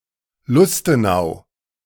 De-Lustenau.ogg